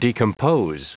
Prononciation du mot decompose en anglais (fichier audio)
Prononciation du mot : decompose